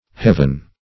Heaven \Heav"en\ (h[e^]v"'n), n. [OE. heven, hefen, heofen, AS.